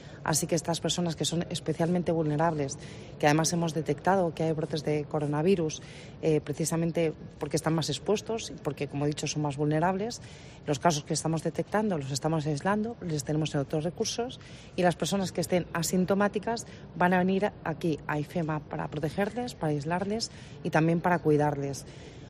Begoña Villacís, vicealcaldesa de Madrid: Son personas especialmente vulnerables